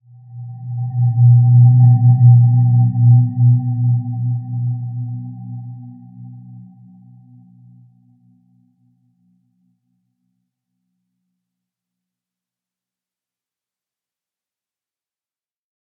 Dreamy-Fifths-B2-mf.wav